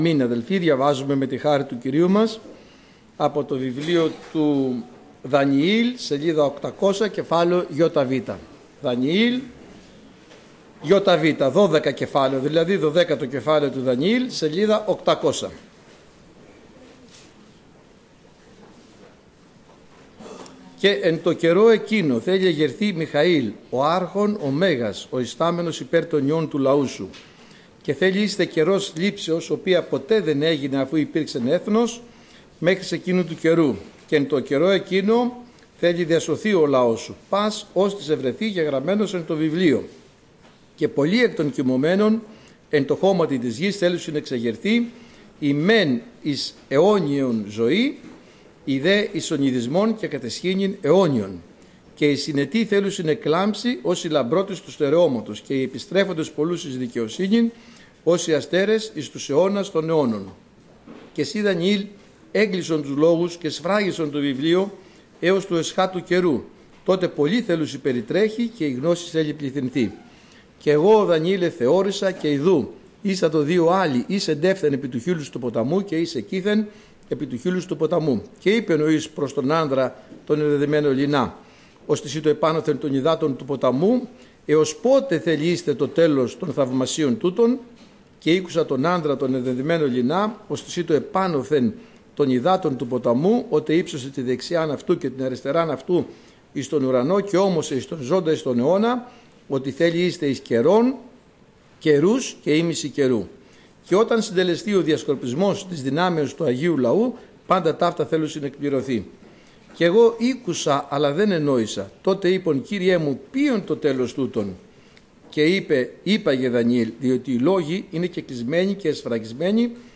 Κήρυγμα Κυριακής